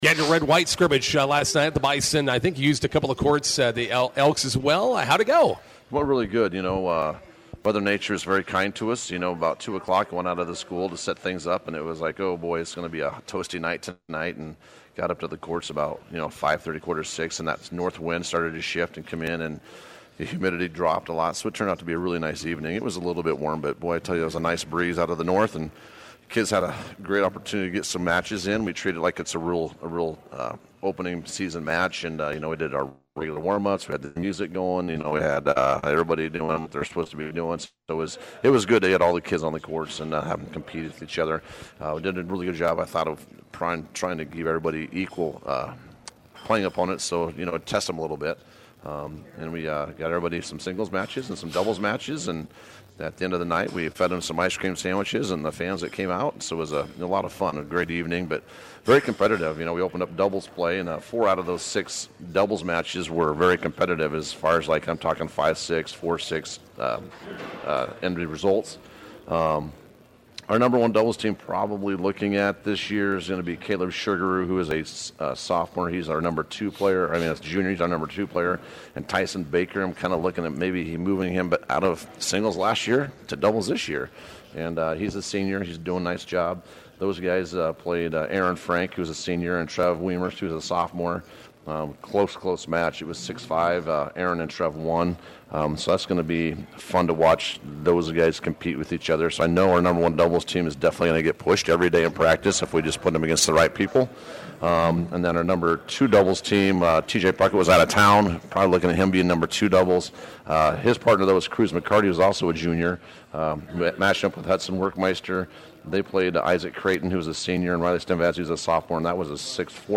INTERVIEW: Bison boys tennis opens season with home matches today and Saturday.